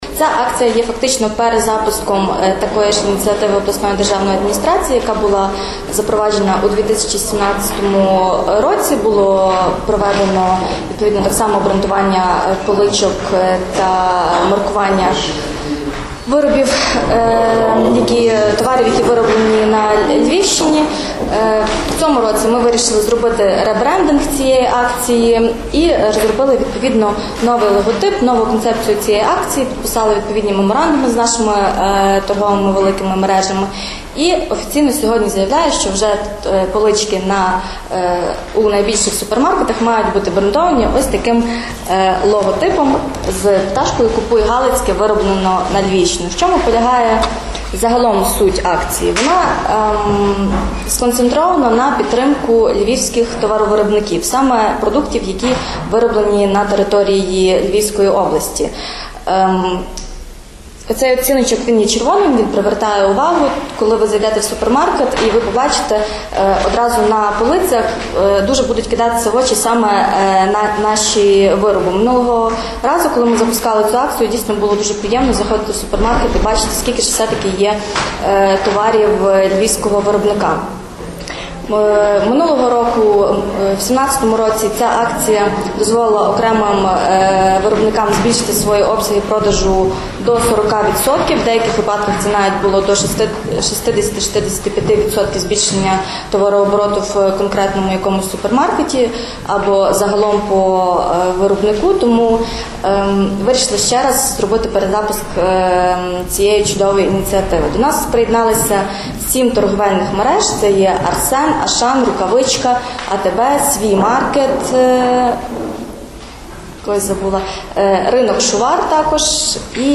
Як розповіла сьогодні, 30 липня, під час брифінгу